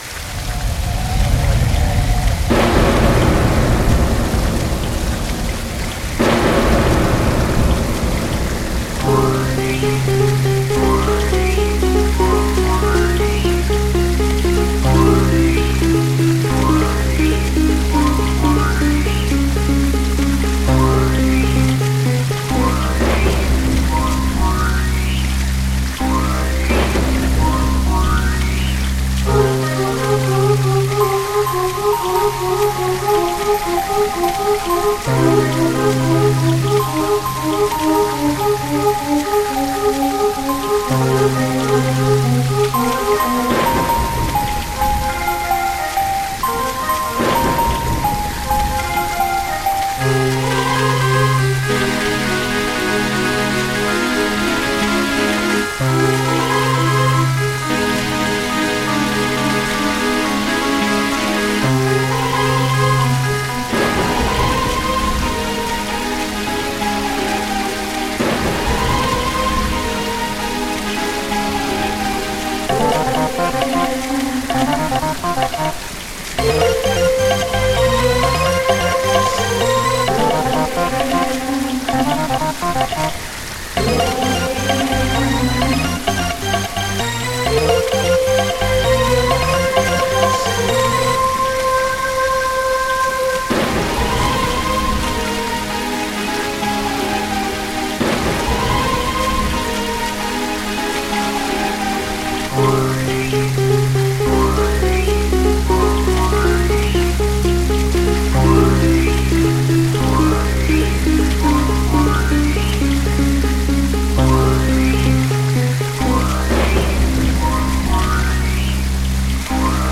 Ces morceaux ont été créés avec divers logiciels de MAO : éditeurs de partition, séquenceurs, trackers et autres...